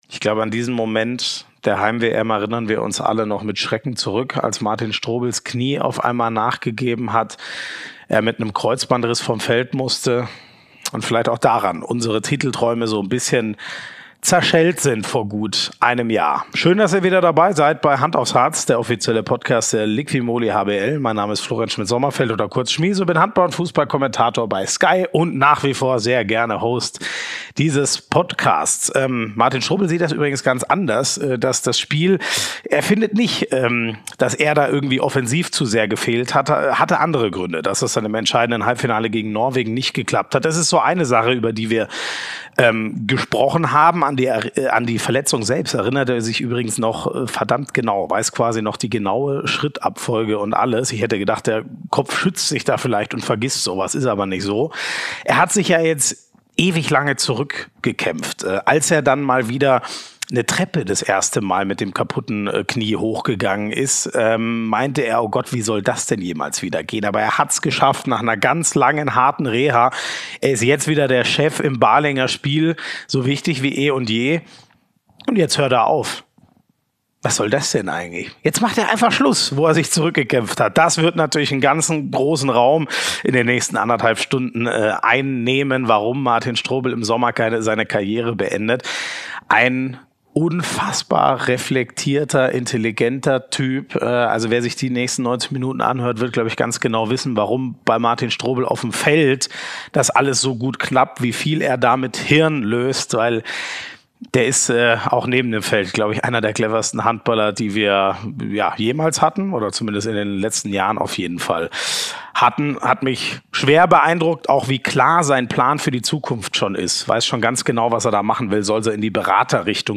Spannende Insider-Informationen und tiefgründige Interviews mit den besten Gästen aus dem Handballsport